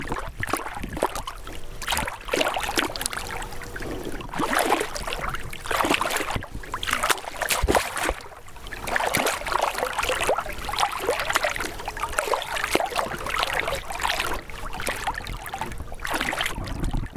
물소리.mp3